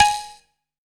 Index of /90_sSampleCDs/AKAI S6000 CD-ROM - Volume 5/Africa/KALIMBA